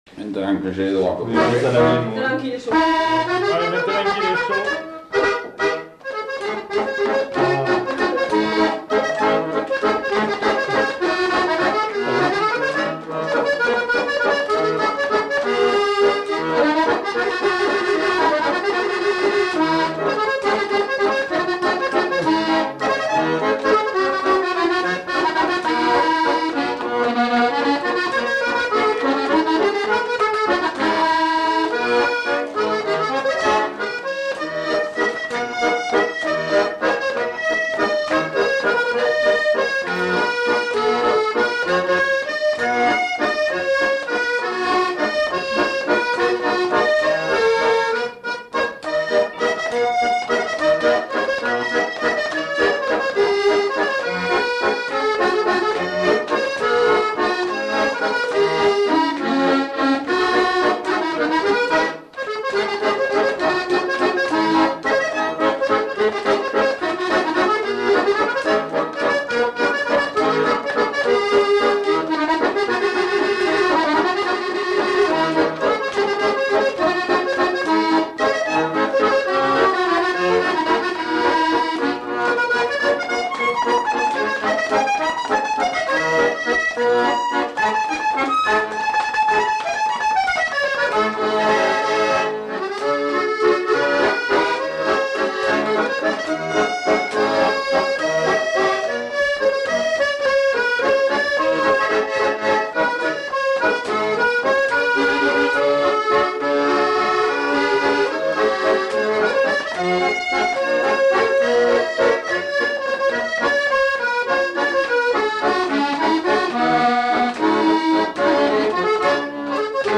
Aire culturelle : Gabardan
Lieu : Durance
Genre : morceau instrumental
Instrument de musique : accordéon chromatique
Danse : valse